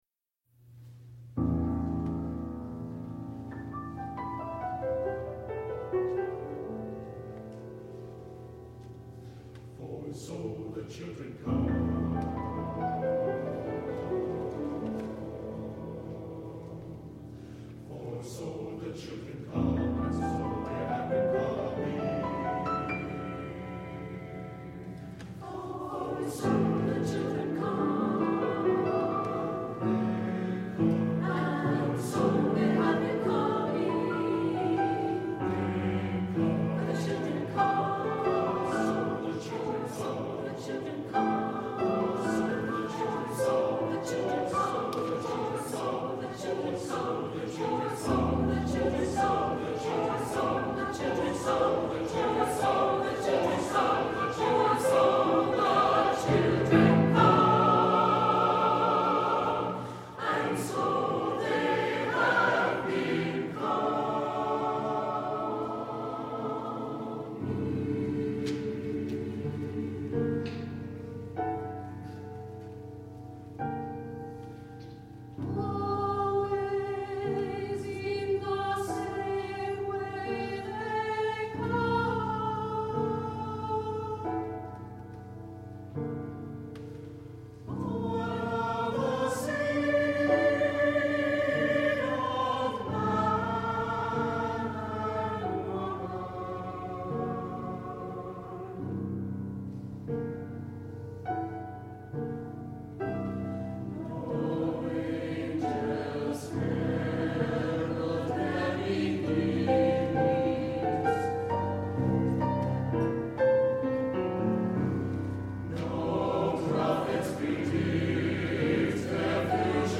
SATB, piano
Opening with stillness and expectation